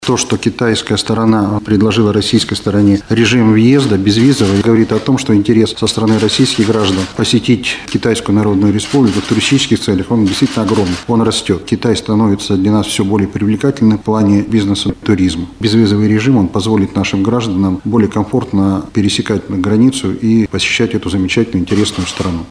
Как отметил на пресс-конференции представитель МИД России в Екатеринбурге Александр Харлов, интерес свердловчан к Китаю — огромный, и он продолжает расти.